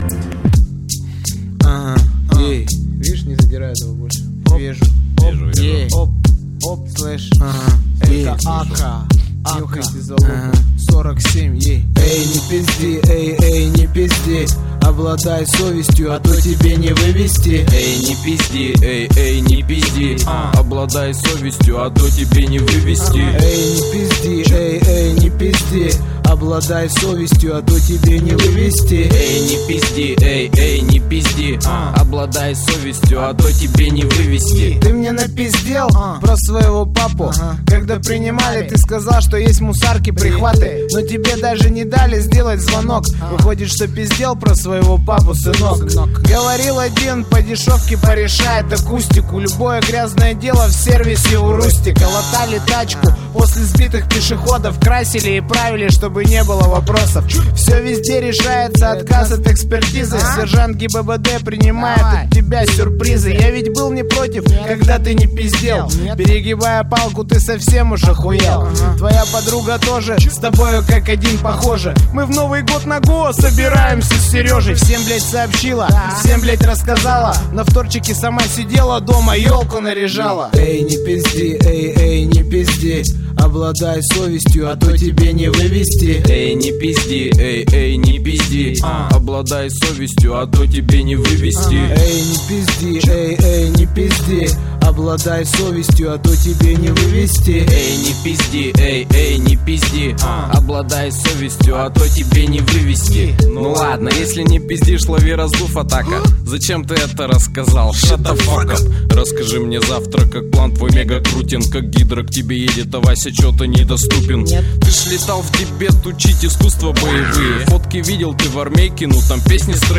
Главная » Каталог музыки » Русский рэп